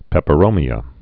(pĕpə-rōmē-ə)